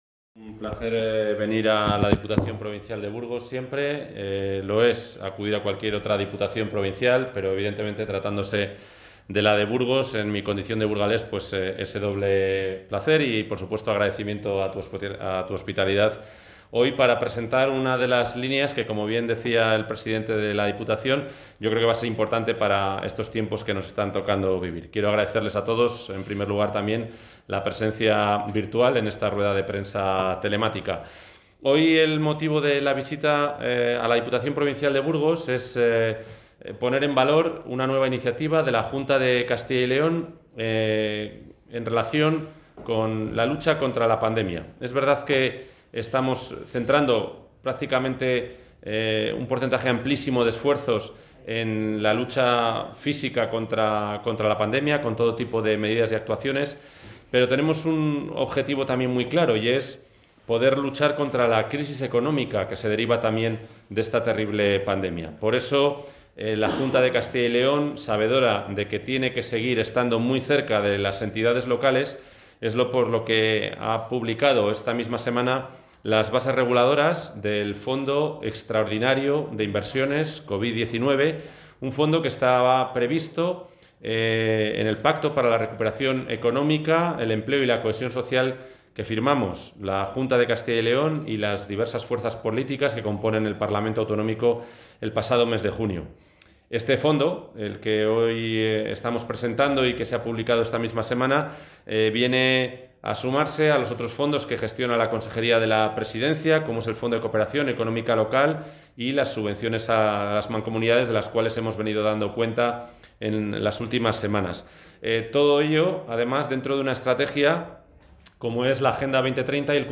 Intervención del consejero de Presidencia.